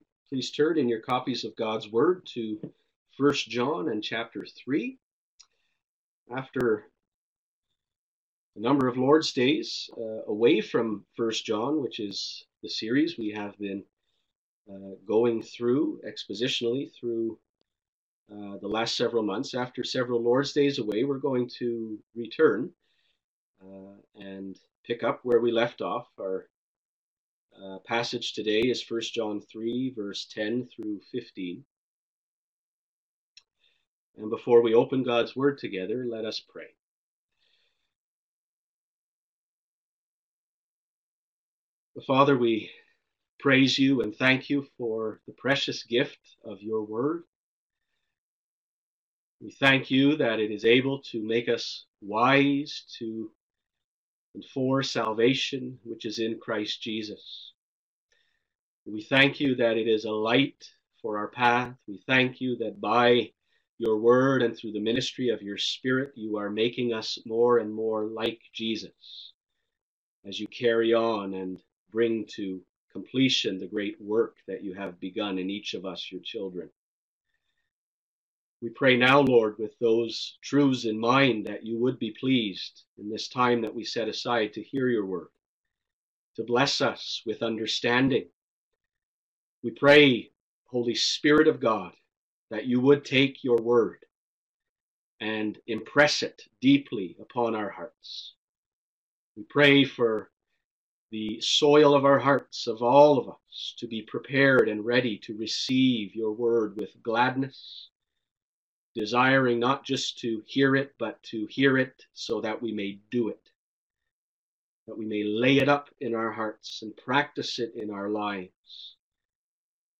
No Identity Crisis Here! (1 John 3:10-15) *Live-streamed Recording*